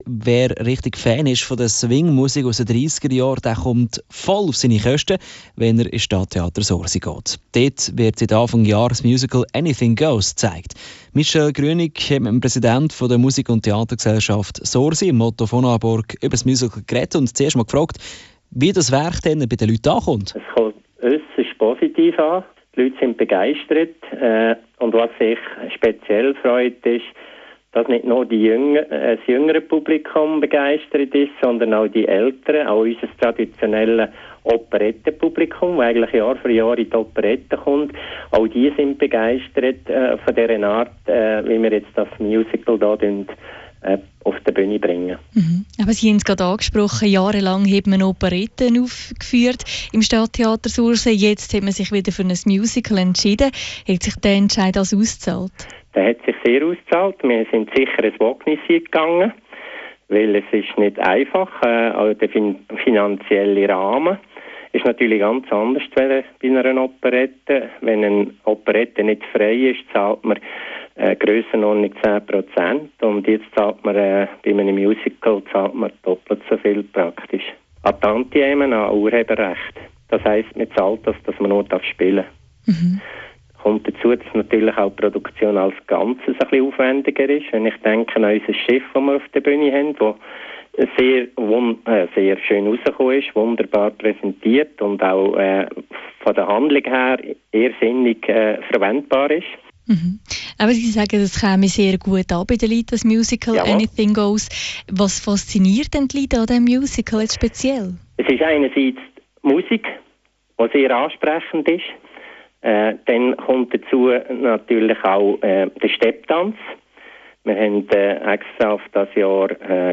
anything_goes_interview_radio_central_2014.mp3